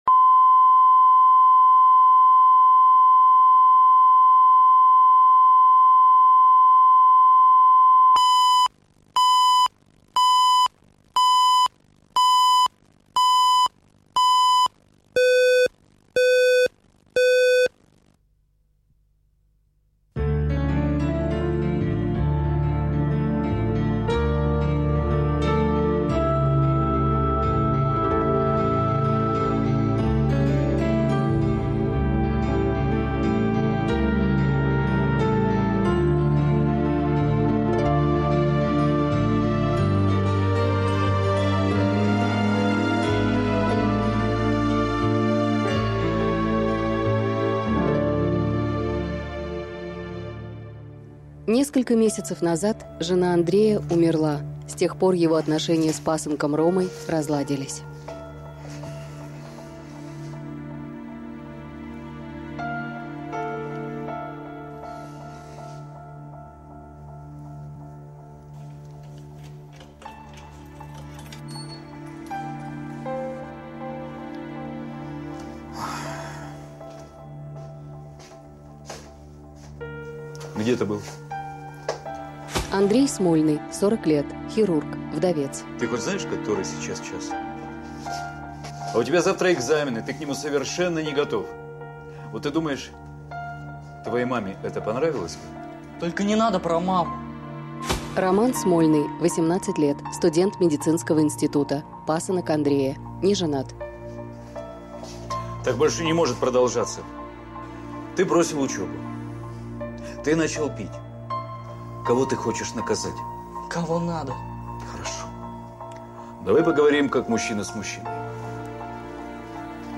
Аудиокнига Папенькин сынок | Библиотека аудиокниг
Прослушать и бесплатно скачать фрагмент аудиокниги